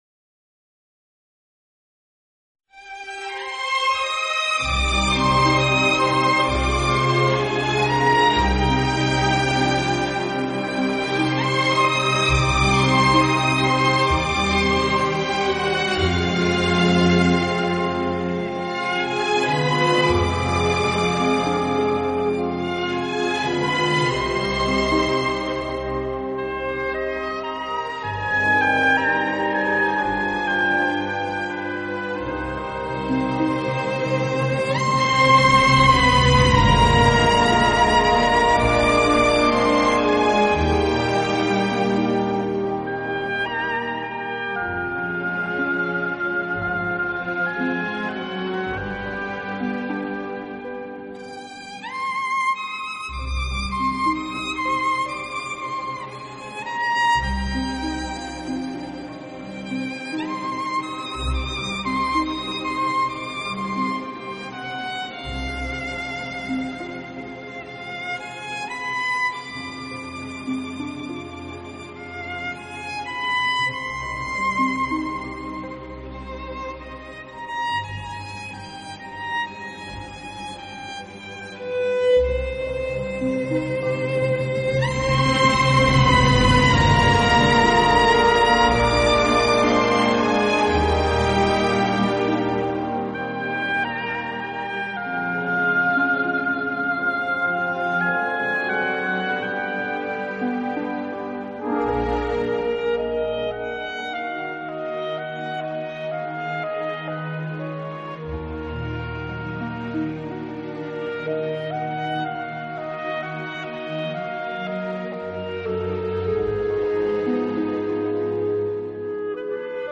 【小提琴专辑】